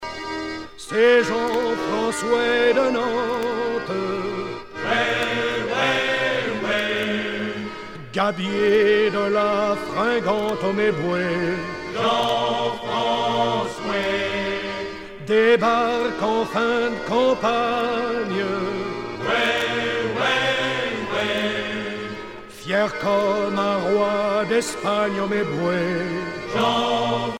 Genre laisse